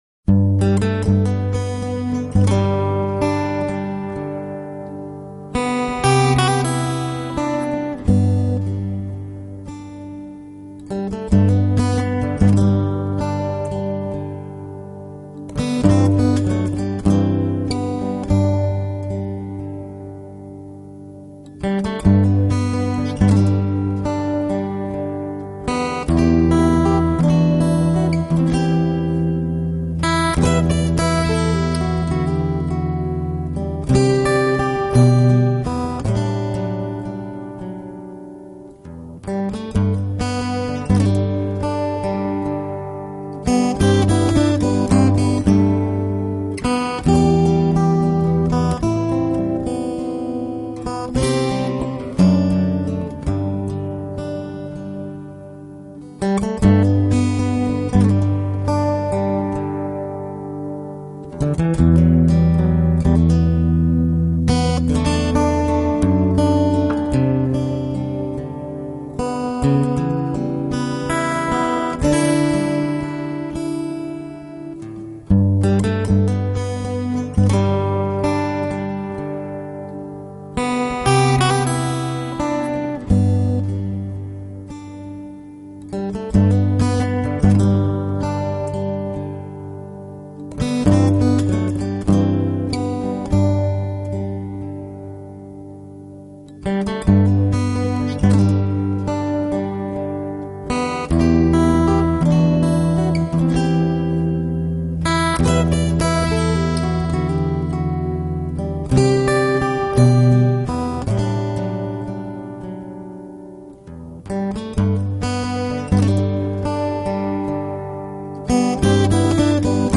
【纯音乐】